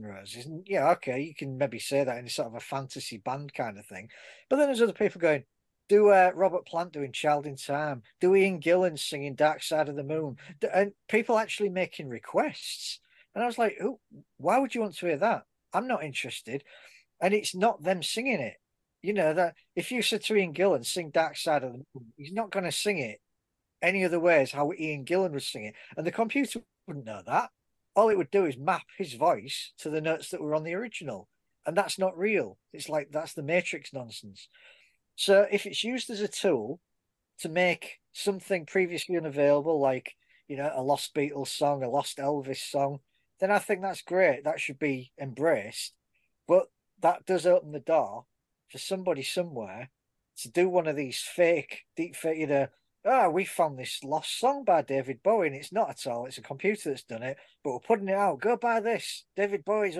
Play Rate Exclusive Listened List Bookmark Share Get this podcast via API From The Podcast Fireworks Pyrotechnics The new music discussion show from Fireworks Rock & Metal Magazine. Want to listen to some real debate about popular Rock and Metal music matters?